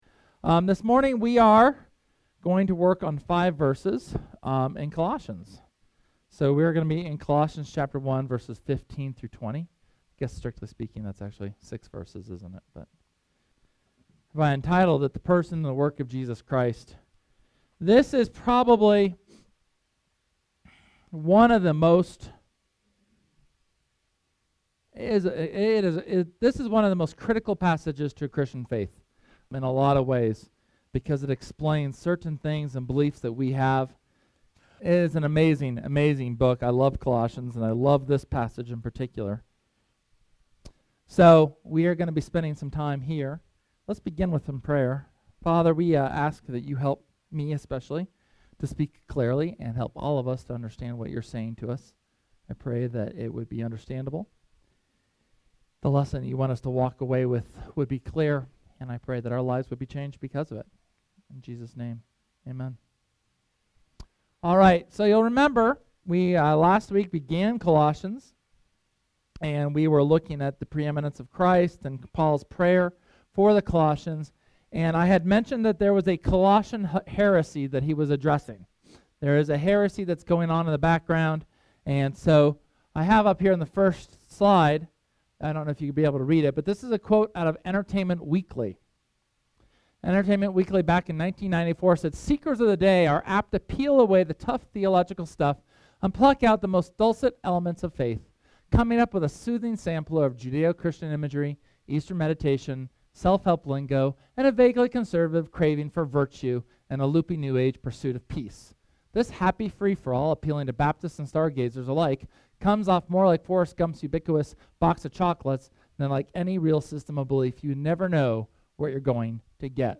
SERMON: The Person and Work of Jesus – Church of the Resurrection